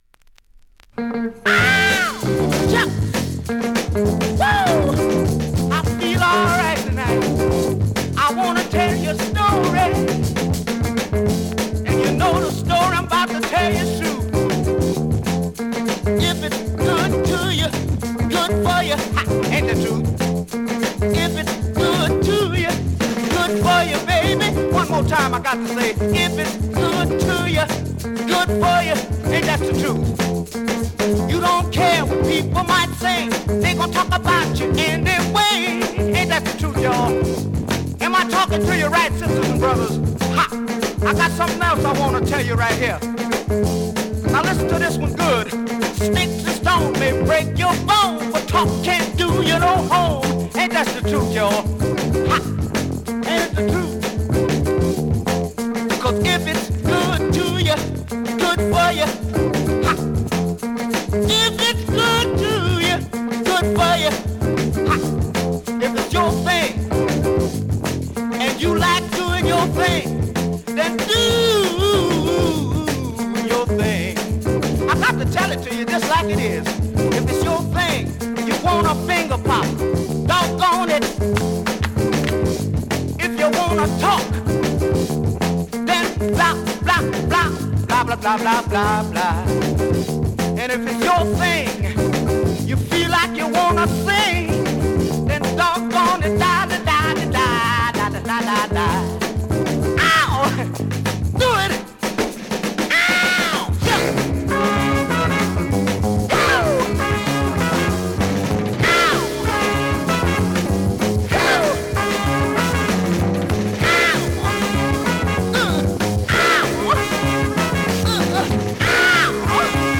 ◆盤質両面/VG+ ◆音質は良好です。
現物の試聴（両面すべて録音時間５分１４秒）できます。